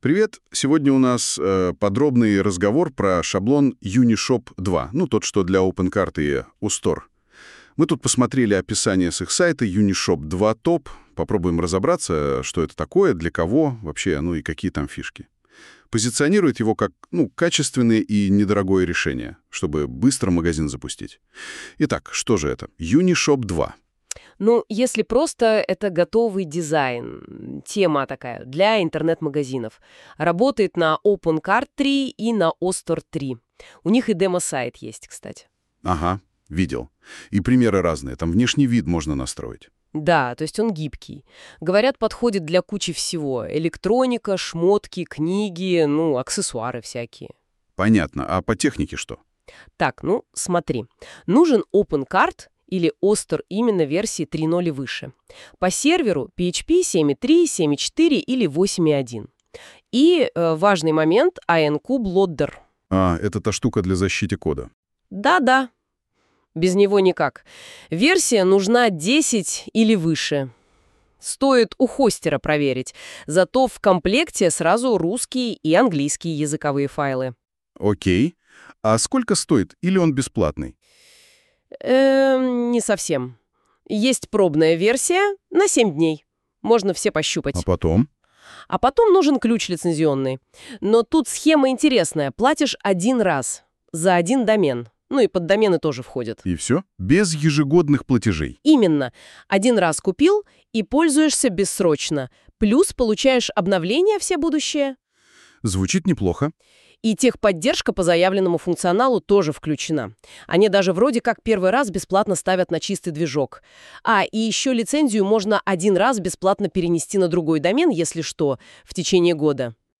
Аудио описание от ИИ
UniShop2_review_from_NotebookLM.mp3